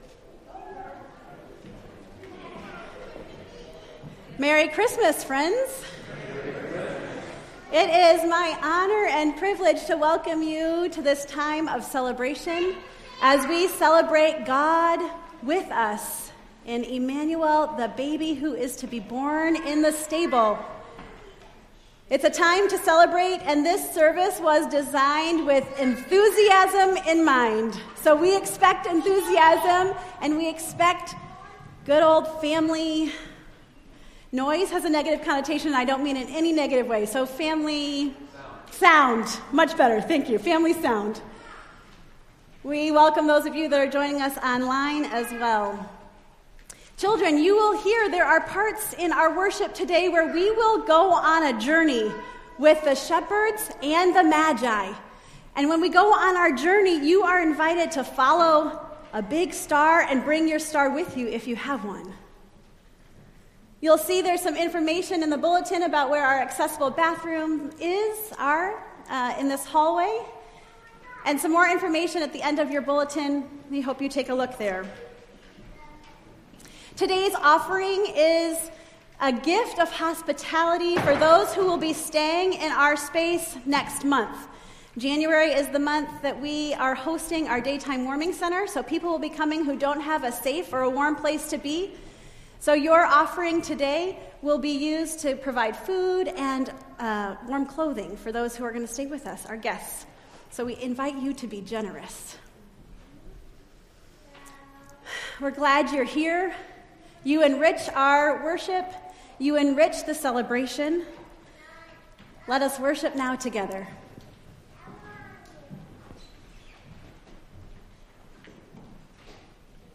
Entire December 24th Service